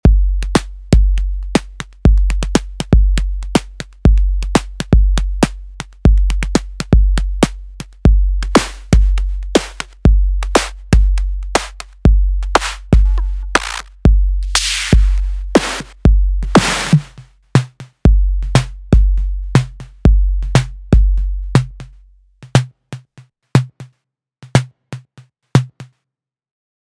How to make a snare sound in Live
Here’s how the snare synth sounds, and you’ll notice that by turning a few macros it suddenly sounds wildly different!
snare-example.mp3